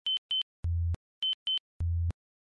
火车通行
描述：一列火车从左到右经过。麦克风在铁轨附近。用Sony FX1相机录制。
标签： 左到右 传球 立体声 火车
声道立体声